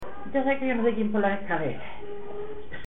Agrón (Granada) Icono con lupa
Secciones - Biblioteca de Voces - Cultura oral